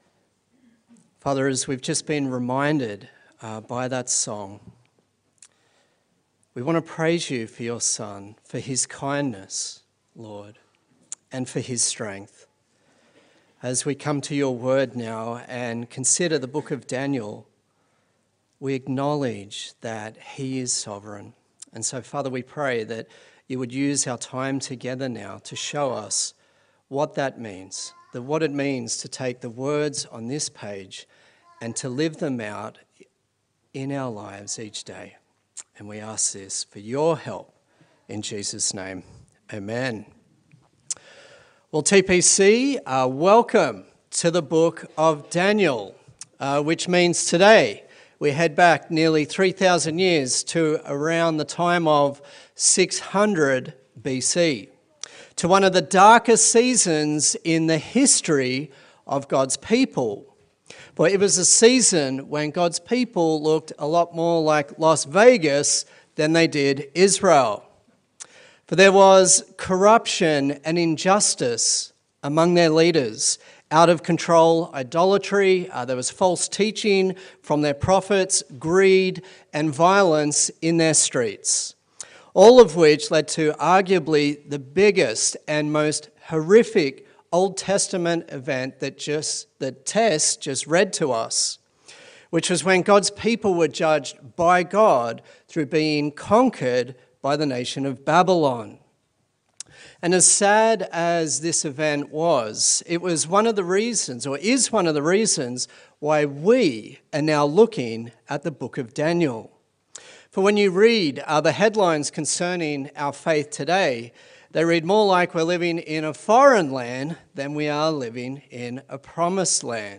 Daniel Passage: Daniel 1 Service Type: Sunday Service